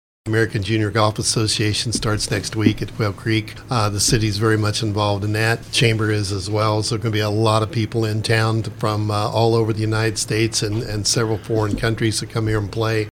That was Robinson Mayor, Mike Shimer.